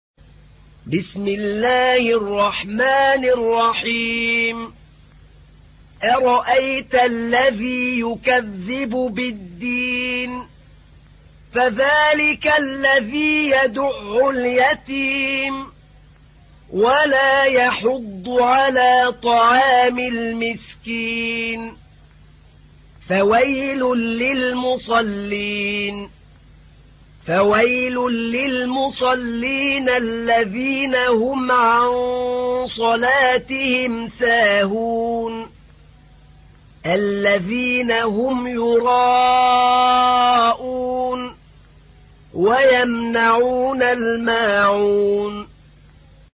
تحميل : 107. سورة الماعون / القارئ أحمد نعينع / القرآن الكريم / موقع يا حسين